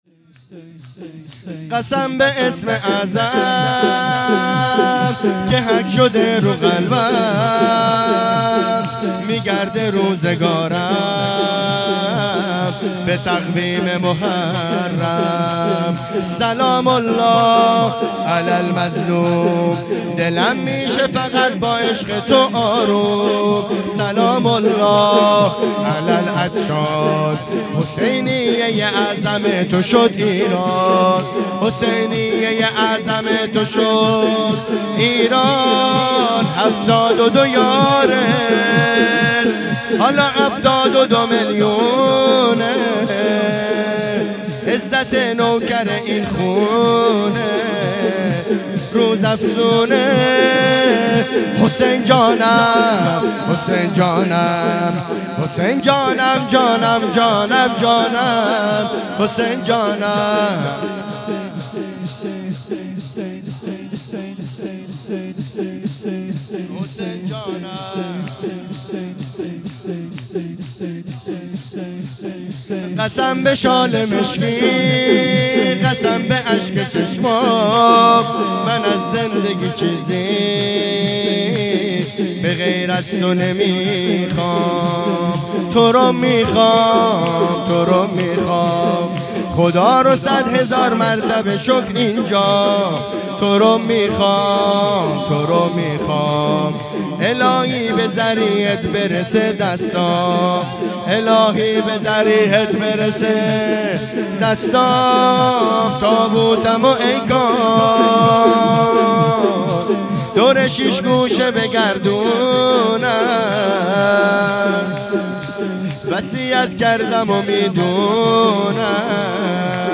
شهادت آقا جوادالائمه 1402